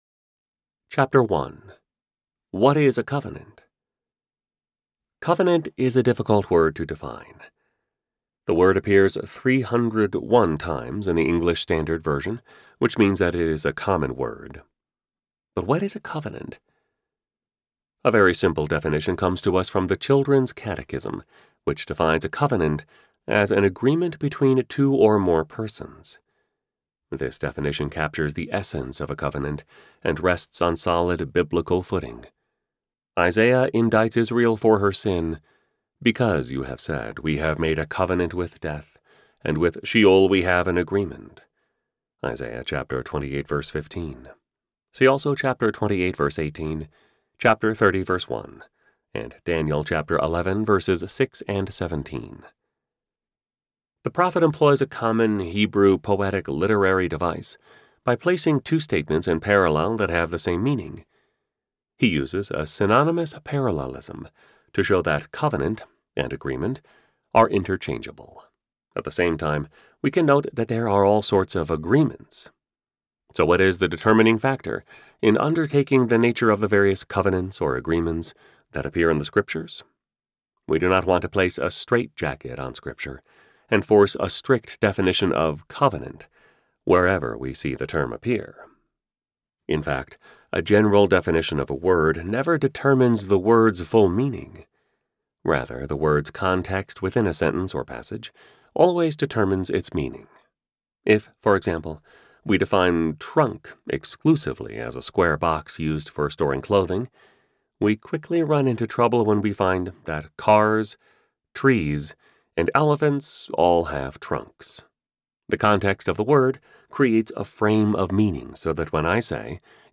Signed, Sealed, Delivered: J.V. Fesko - Audiobook Download, Book | Ligonier Ministries Store